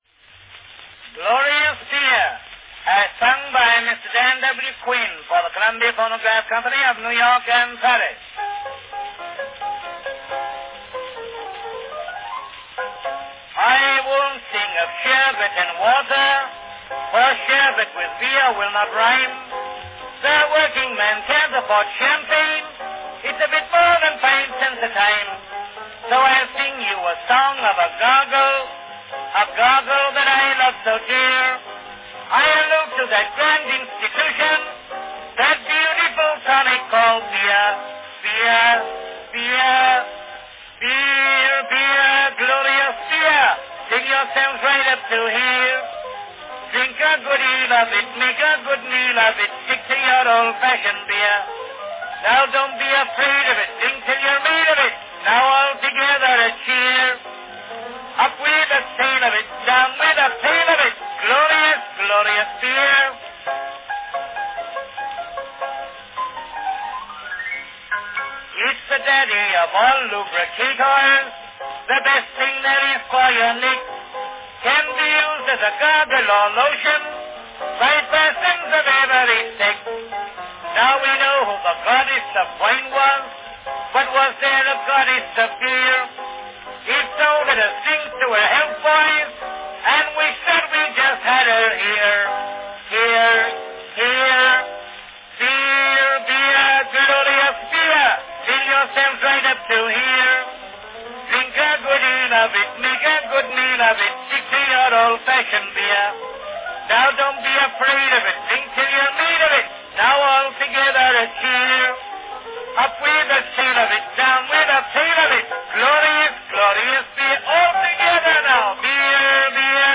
A spirited performance by Dan W. Quinn, Glorious Beer, from 1899.
Cylinder # 5063 (5-inch "grand" cylinder)
Category Tenor solo
Performed by Dan W. Quinn
Announcement "Glorious Beer, as sung by Mister Dan W. Quinn, for the Columbia Phonograph Company of New York and Paris."
Dan W. Quinn was one of the most prolific tenors of the time, recording for nearly every record company: